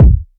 pcp_kick09.wav